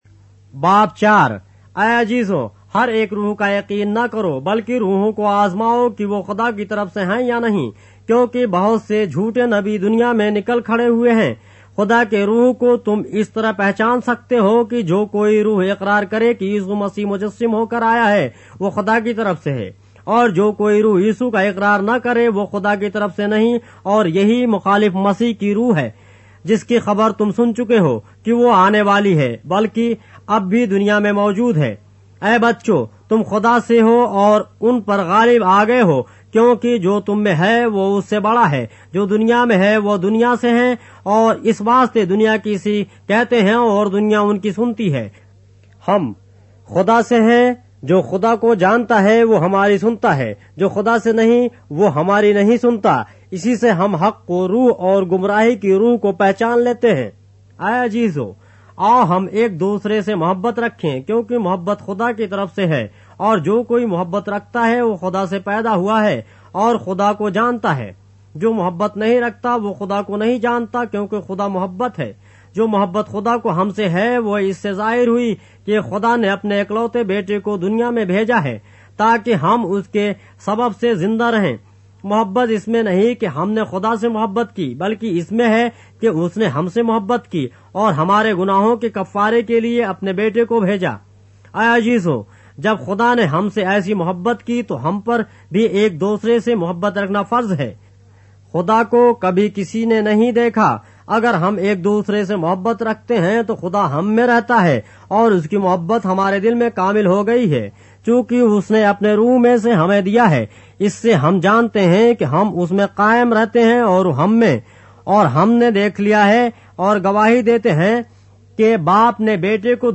اردو بائبل کے باب - آڈیو روایت کے ساتھ - 1 John, chapter 4 of the Holy Bible in Urdu